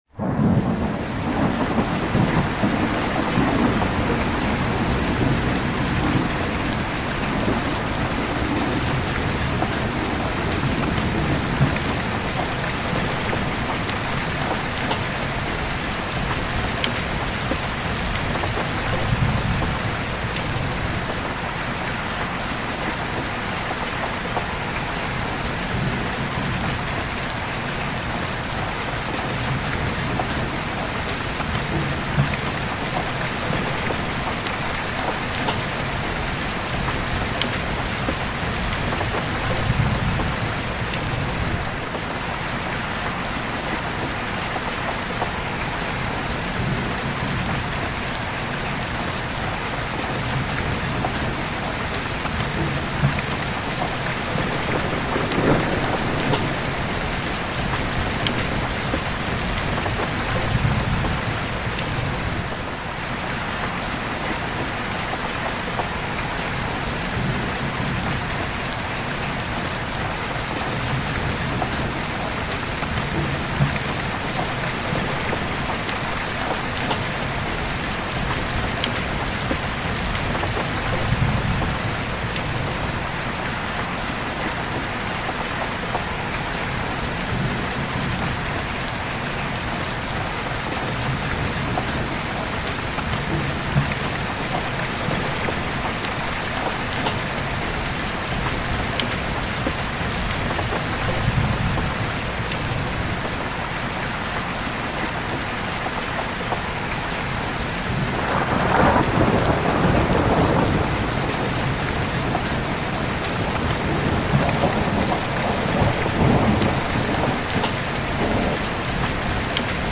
Rainstorm Sleep Ambience — Thunder Rolling Over a Tin Roof